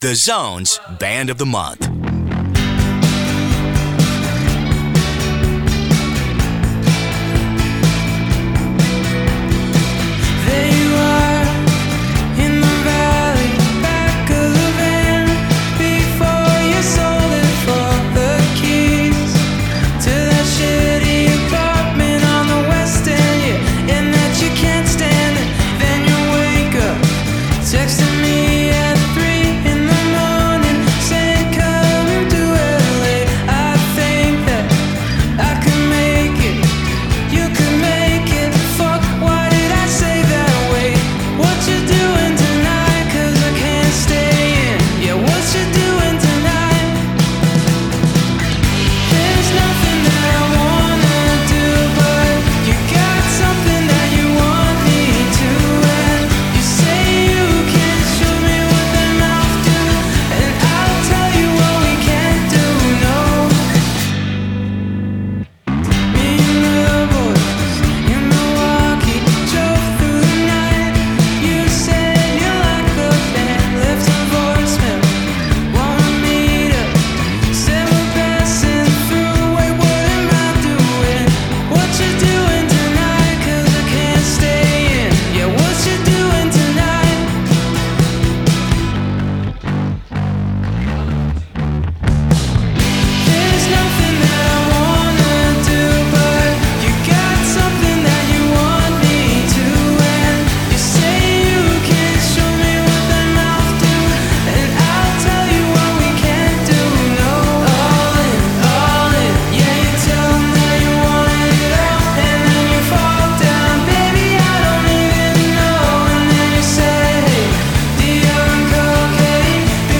Vocals/Guitar
alternative rock duo from Vancouver Island